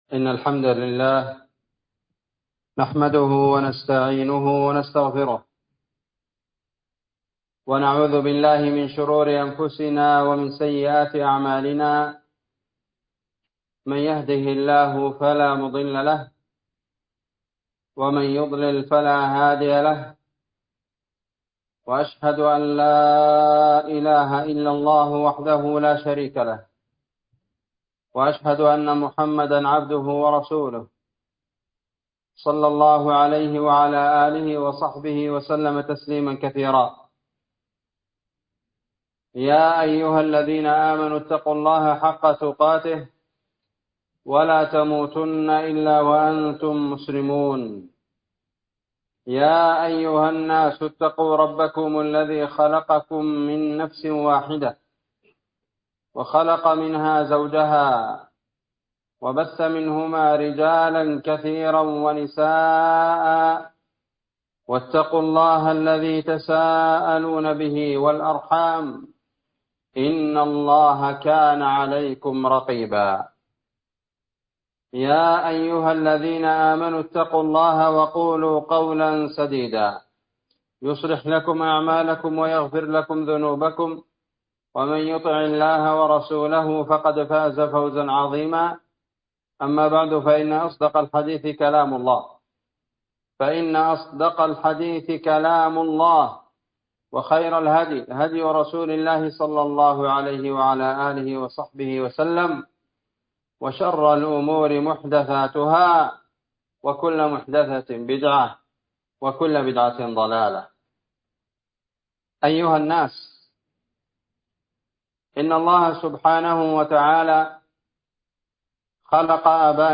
خطبة جمعة
إندونيسيا- جزيرة سولاويسي- مدينة بوني- قرية تيرونج- مسجد الإخلاص